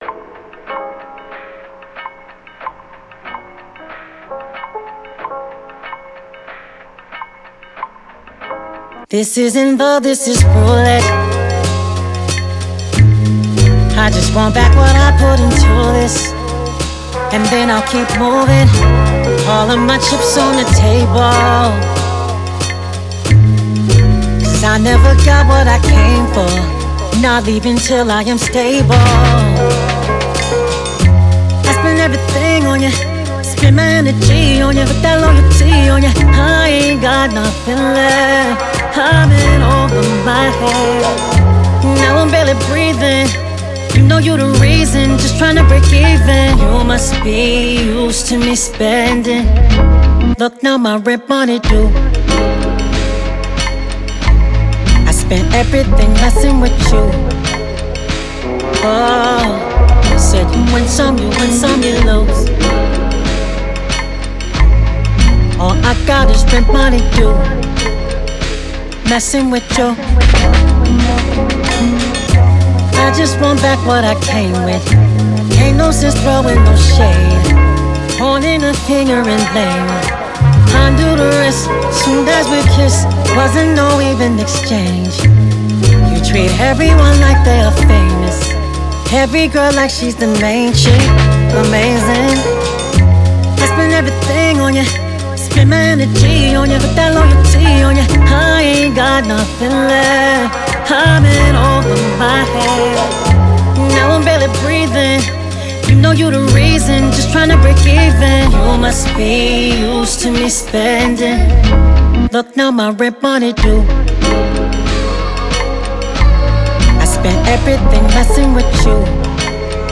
Genre: Urban.